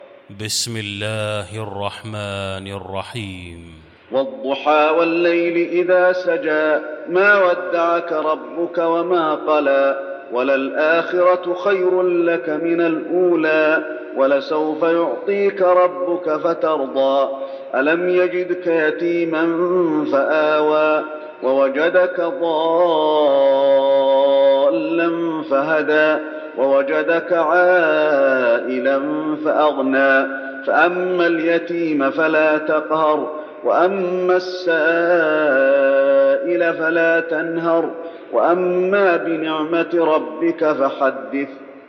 المكان: المسجد النبوي الضحى